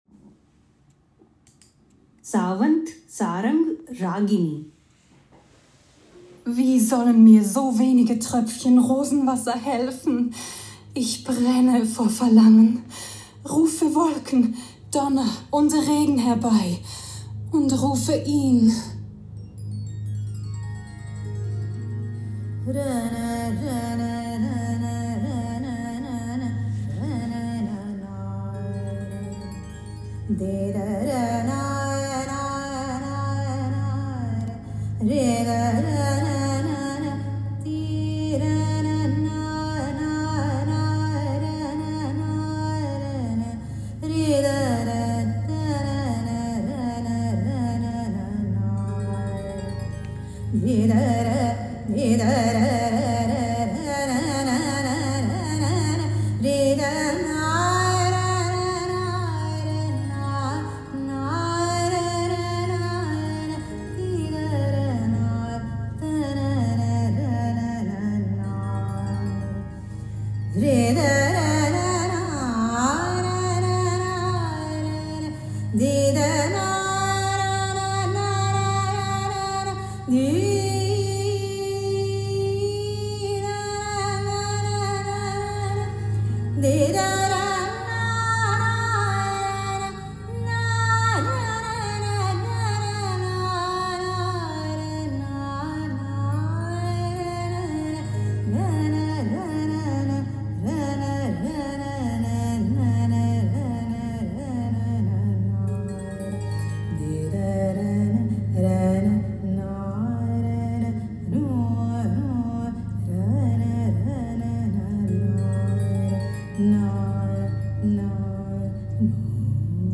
Die klassische indische Musik
In einem Konzert folgen die Musiker keiner ausgeschriebenen Partitur. Die Ragas bilden die Skala der Töne, zu denen sie improvisieren.